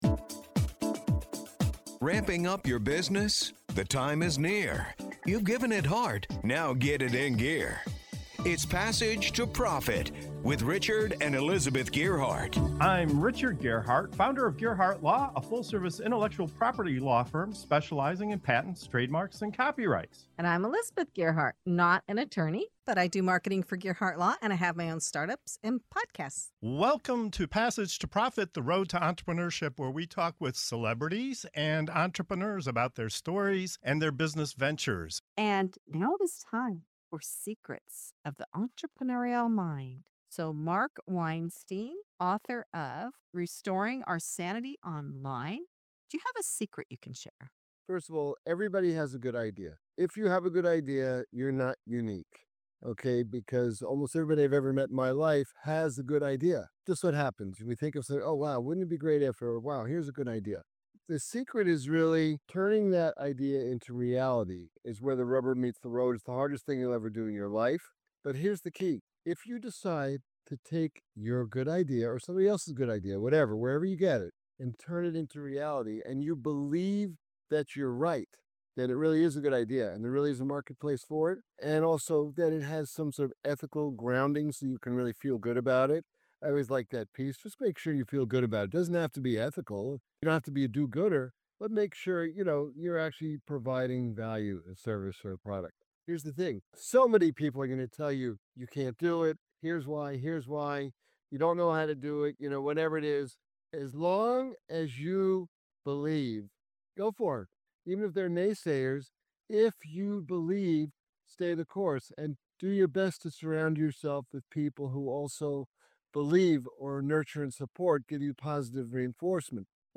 In this segment of "Secrets of the Entrepreneurial Mind", our panel of entrepreneurial experts pull back the curtain on the real inner game of success.